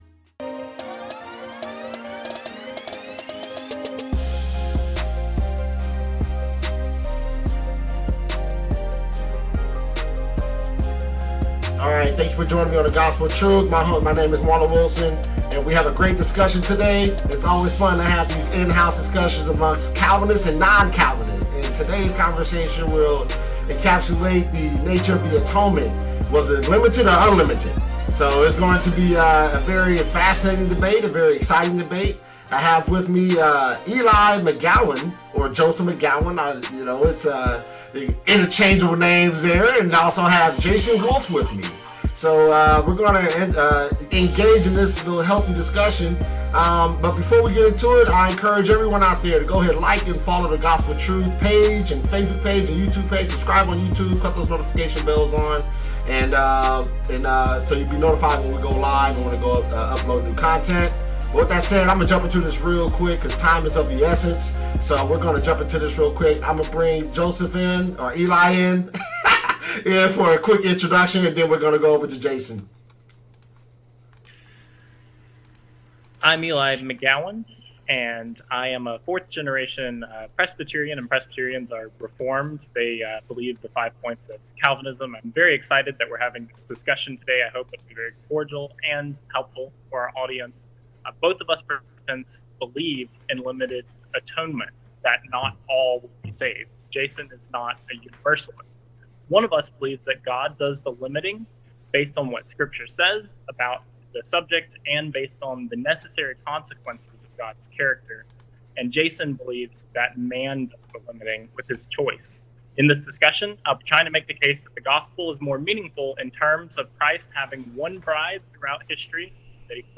Debate: Is the Atonement Limited or Unlimited?